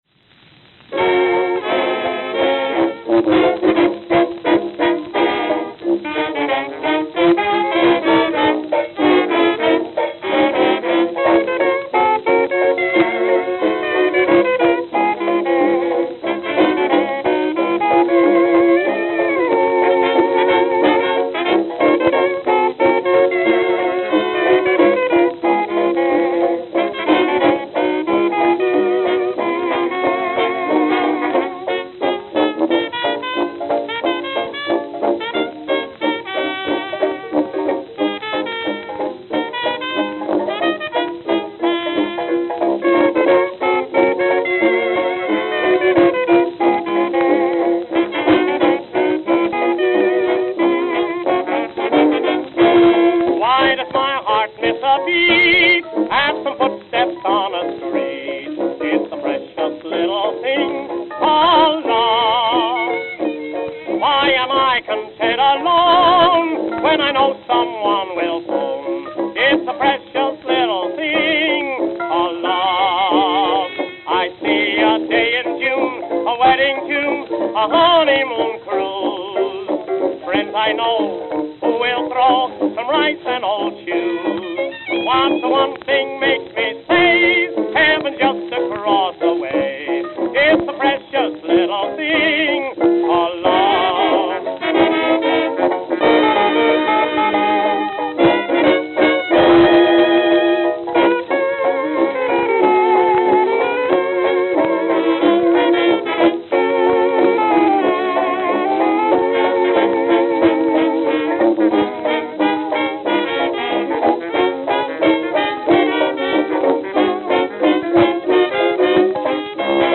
All records here are acoustically-recorded unless noted.